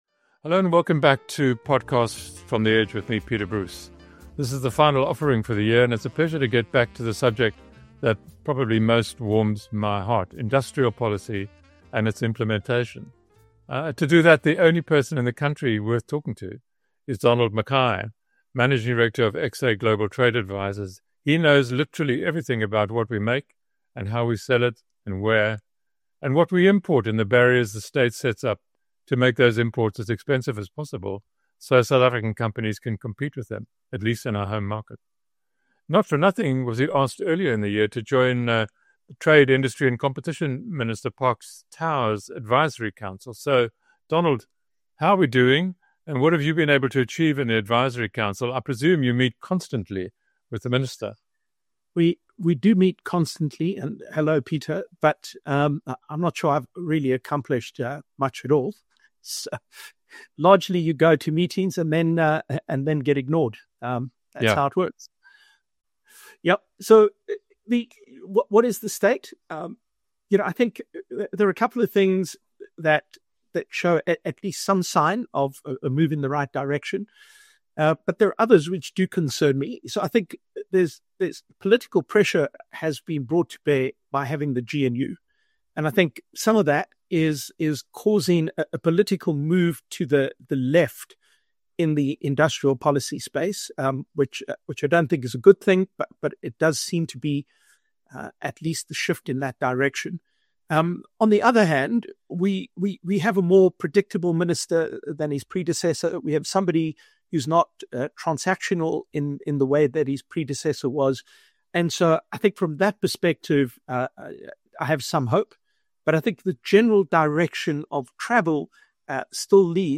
… continue reading 199 episodes # News Talk # News # South Africa # TimesLIVE